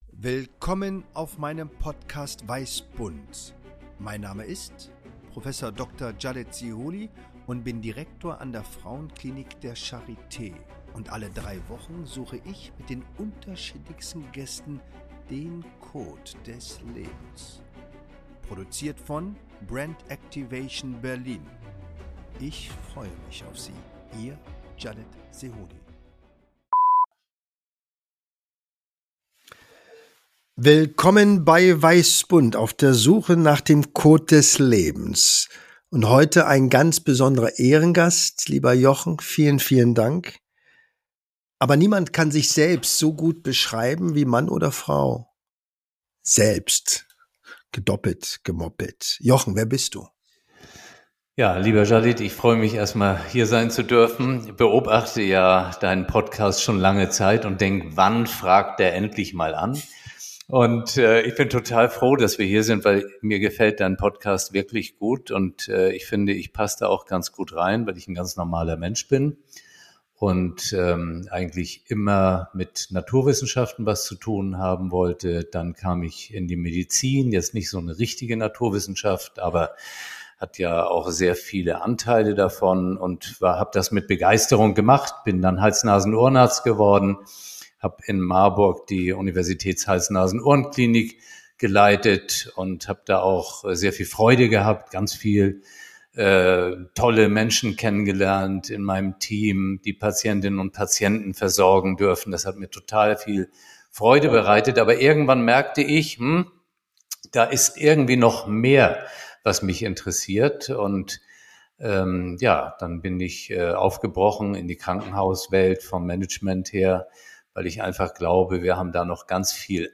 Spontan, intuitiv, ohne Skript, Improvisation pur! Authentisch, ehrlich, direkt!